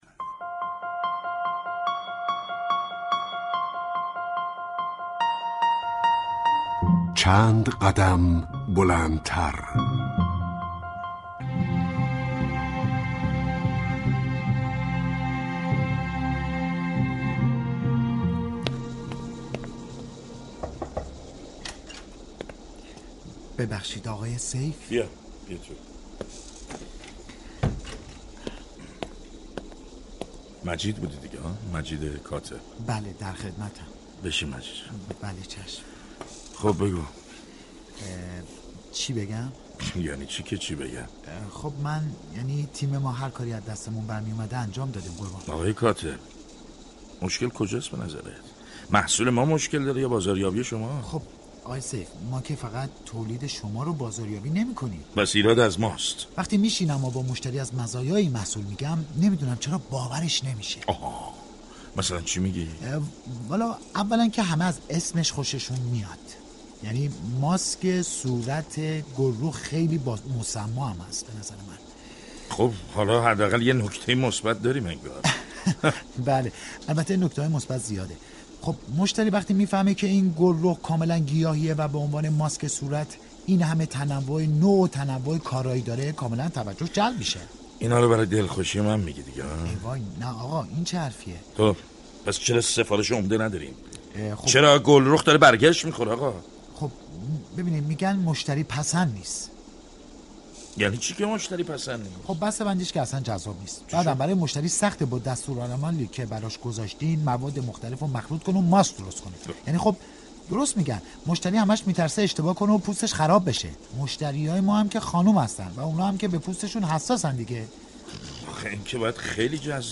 از روز پنج شنبه ،16 آذر ماه ، نمایش رادیویی